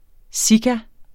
Udtale [ ˈsiga ]